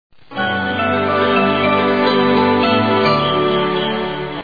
Trailer sting with added sleigh bells